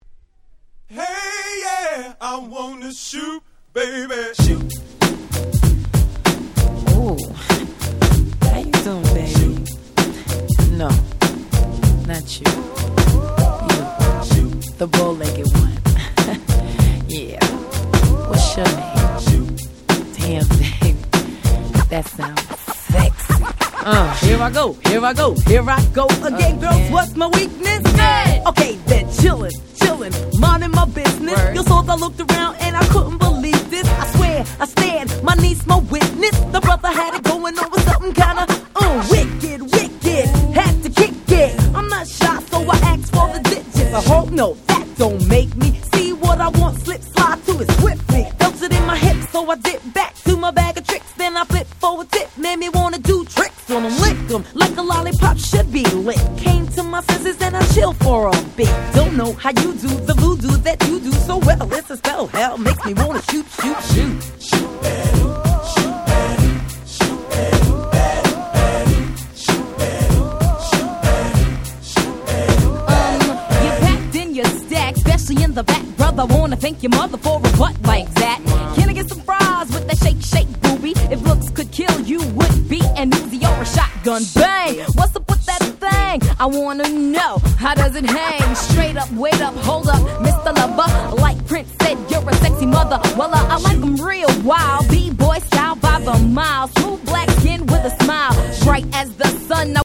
93' Super Hit Hip Hop !!
90's Boom Bap ブーンバップ